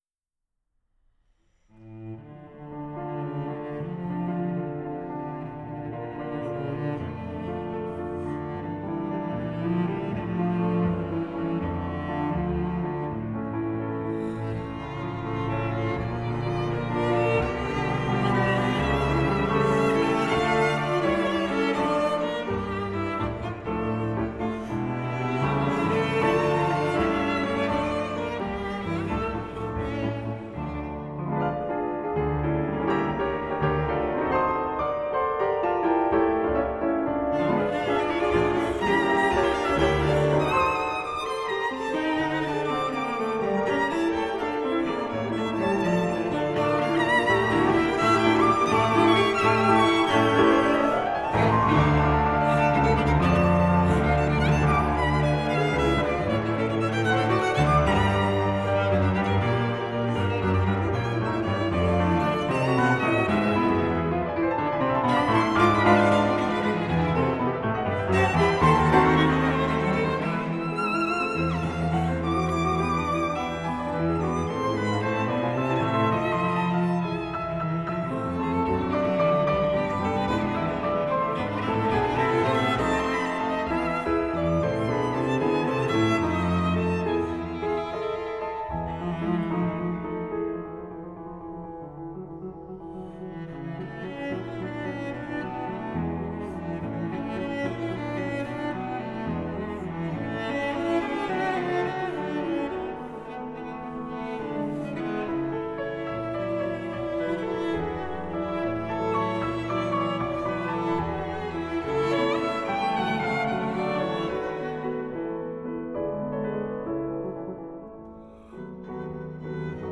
• Opportunity to record and produce professional recordings with Academy artists under the guidance of a music producer
Trio-Chagall-Mendelssohn-Trio-no.1-in-d-minor-op.49-1.-Molto-allegro-ed-agitato.wav